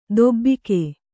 ♪ dobbike